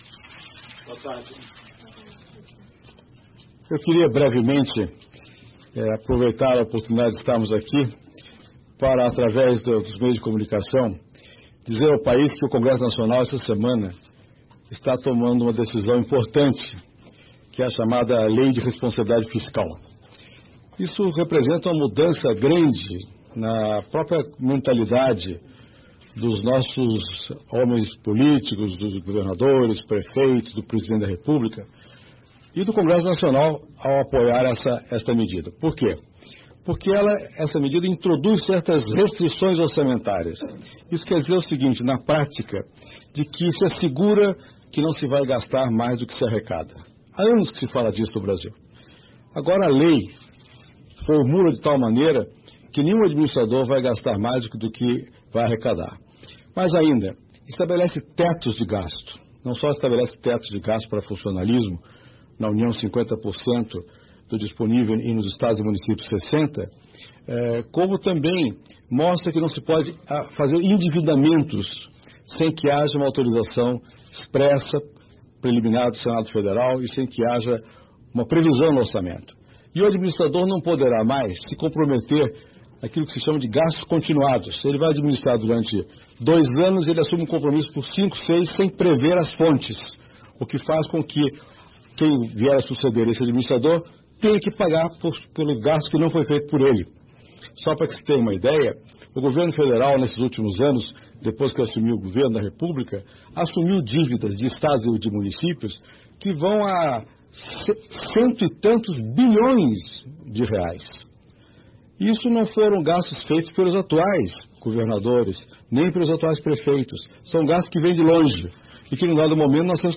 Confira o briefing extraordinário do presidente FHC na sanção da Lei de Responsabilidade Fiscal: